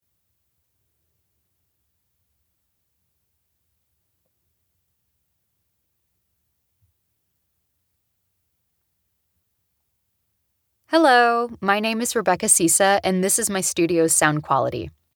Female
TEENS, 20s, 30s
American English
Assured, Bright, Bubbly, Cheeky, Confident, Cool, Corporate, Engaging, Friendly, Natural, Reassuring, Sarcastic, Warm, Witty, Young, Approachable, Conversational, Energetic, Funny, Smooth, Soft, Upbeat
Voice reels
Microphone: Sennheiser MKH 416 & Mojave MA-200